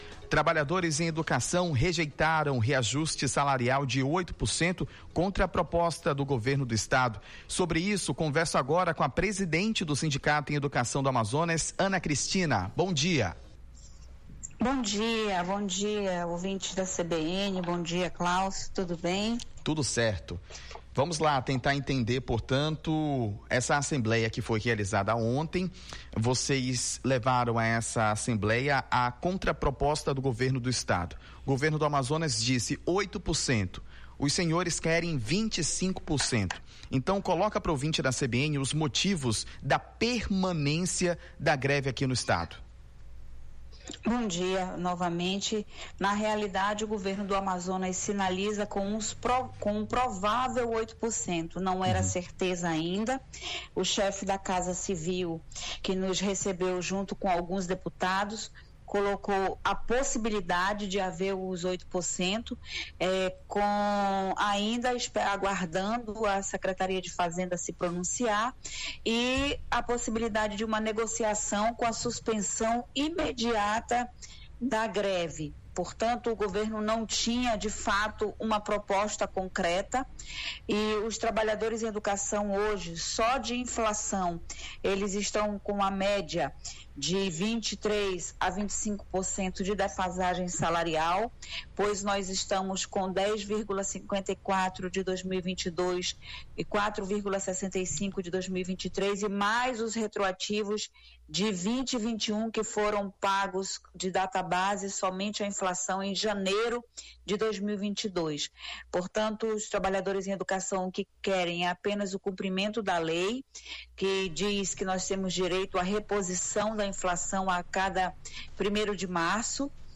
concede entrevista à CBN Amazônia Manaus nesta terça-feira